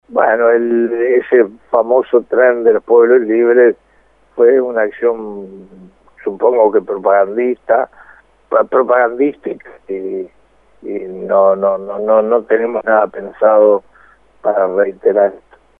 Escuche al ministro sobre el tren